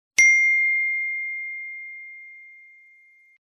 IPHONE ding
iPhone-Notification-Sound.mp3